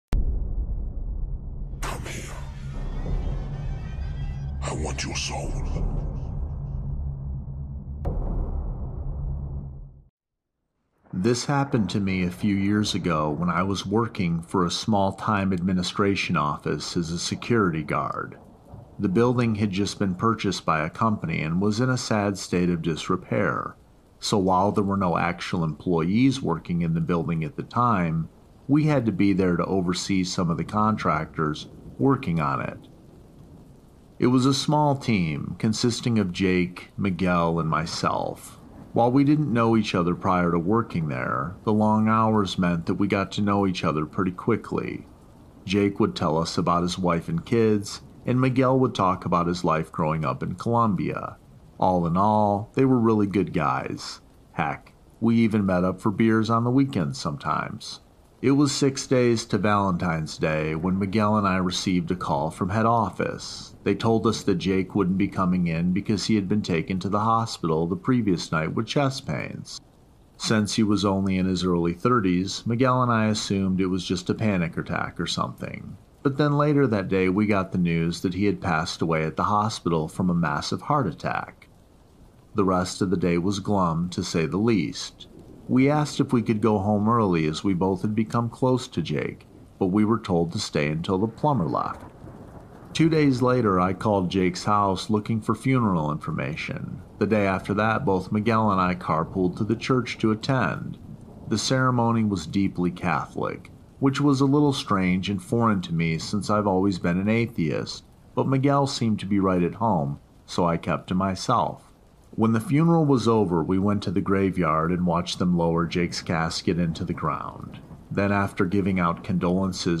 EP 51: 4 Creepypasta Ghost Stories | Scary Horror Story Compilation In The Rain